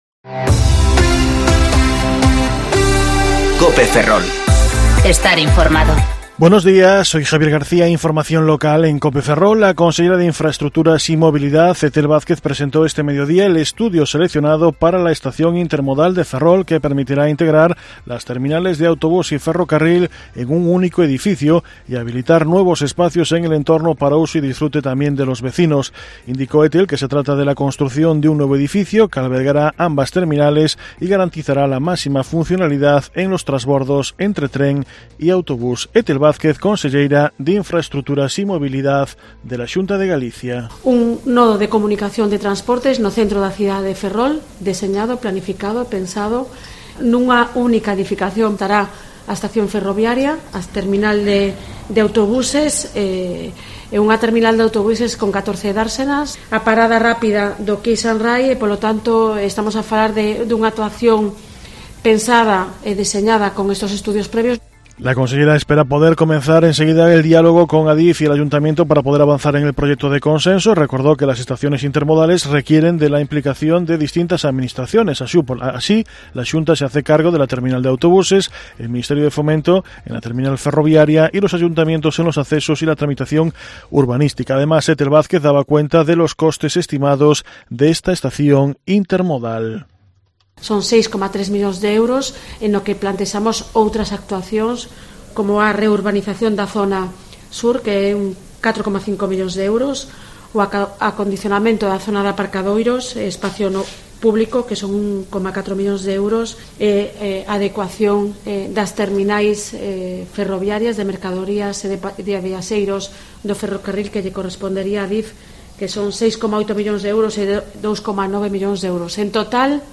Informativo Mediodía Cope Ferrol 29/11/2019 (De 14.20 a 14.30 horas)